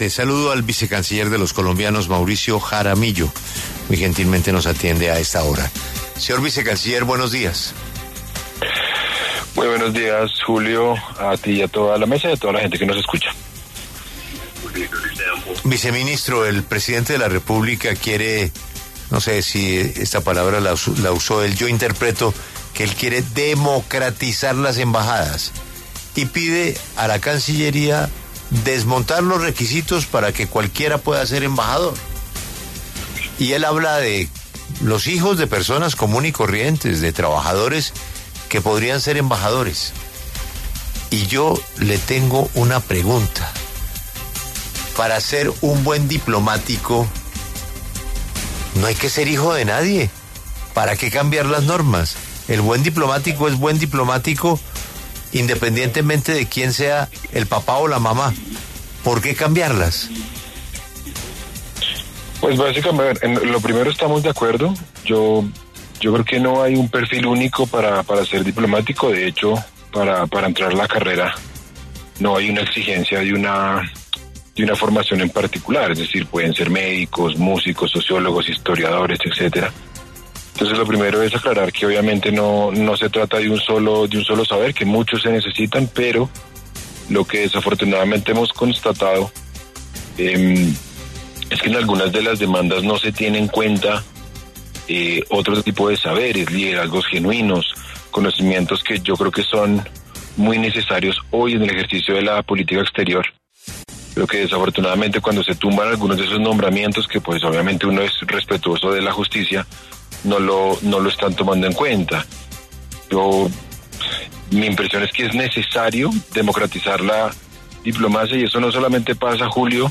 Mauricio Jaramillo, vicecanciller de Colombia, habló en La W sobre la posibilidad de “democratizar” las embajadas y cambiar los requisitos para nombrar embajadores.